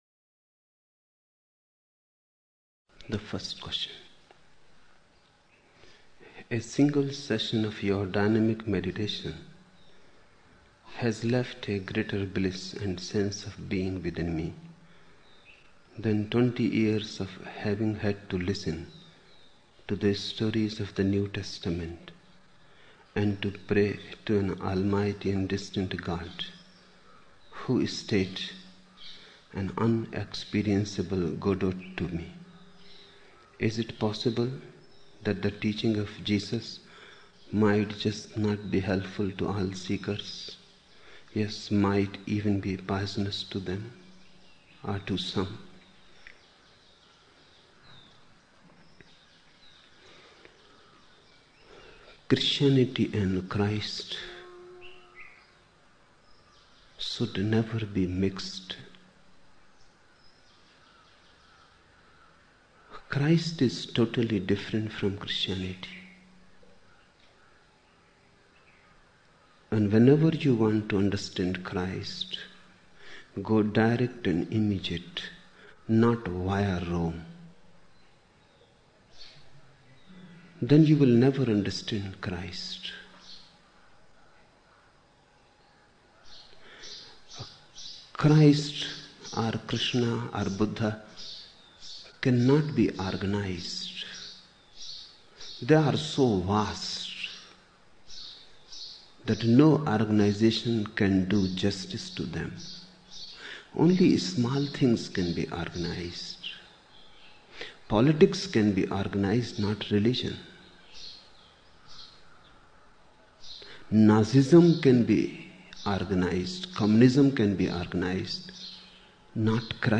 24 October 1975 morning in Buddha Hall, Poona, India